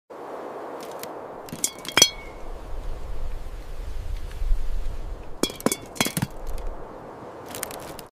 ASMR glass garden vegetables, pumpkin